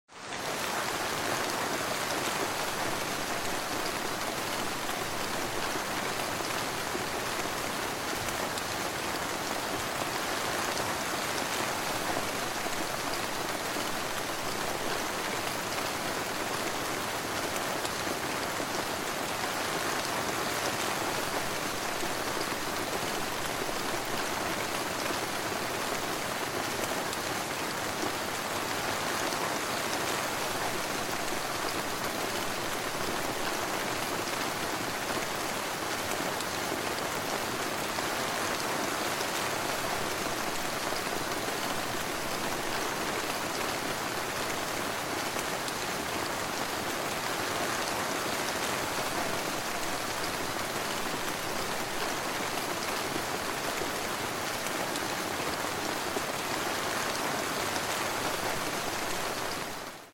Soft rain falling, fire crackling sound effects free download
Soft rain falling, fire crackling outside, and steam rising from a warm kettle. This is the sound of comfort.